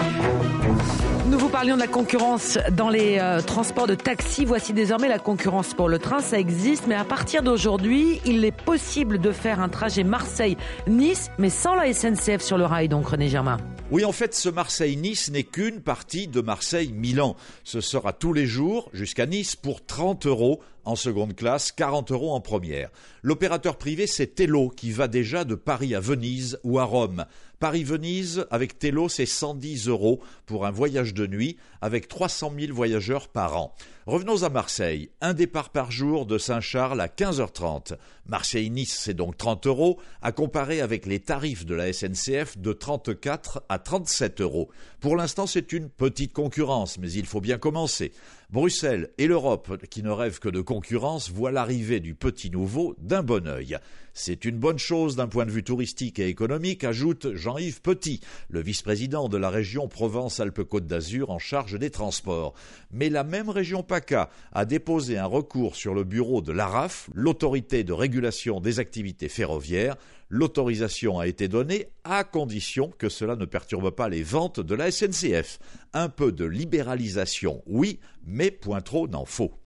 La « chronique éco » de Sud Radio évoque la « petite concurrence » de la compagnie Thello qui lance le 15 décembre une liaison ferroviaire entre Marseille et Nice sur son trajet Marseille-Milan. Le journaliste rappelle la saisine de l’Araf par la région Paca suivie d’un recours devant le conseil d’Etat suite à la décision du régulateur qui considère que cette liaison ne perturbe pas l’équilibre économique de l’activité TER qui fait l’objet d’une convention entre la région et la SNCF.